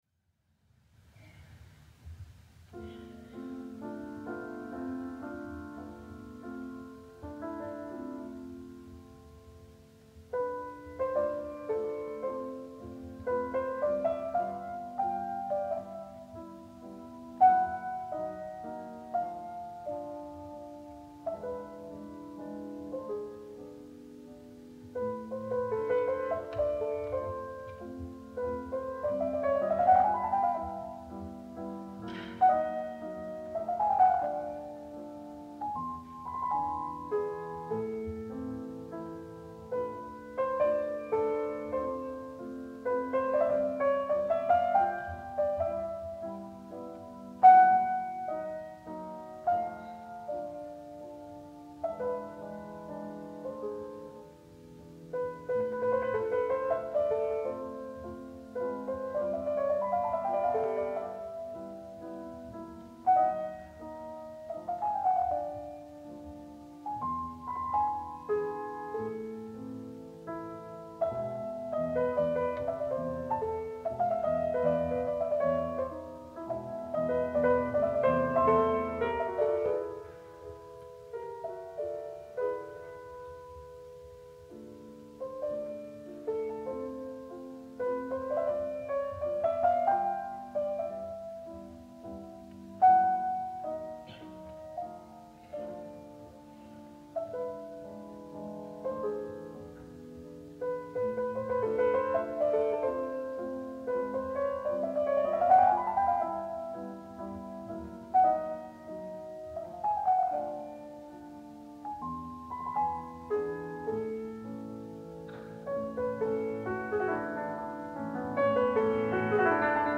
Sviatoslav Richter 1950 – Frédéric Chopin mazurka en la mineur Opus 17 n°4